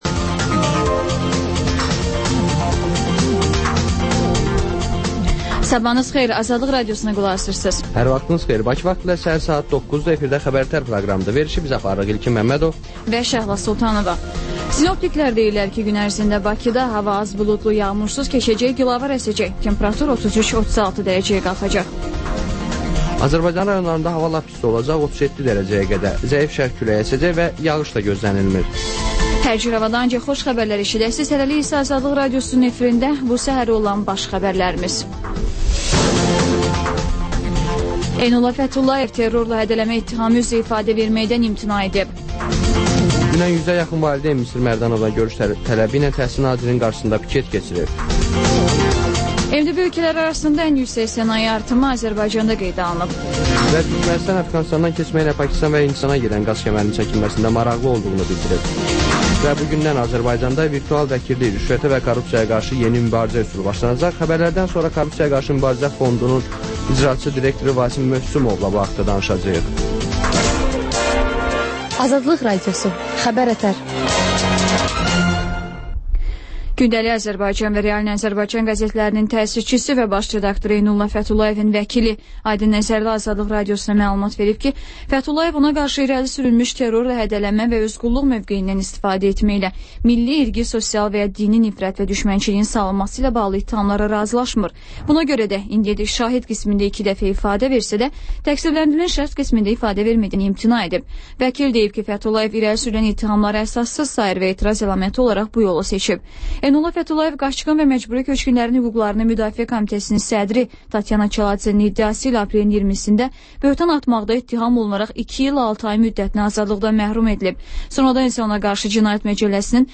Xəbər-ətər: xəbərlər, müsahibələr, sonra 14-24: Gənclər üçün xüsusi veriliş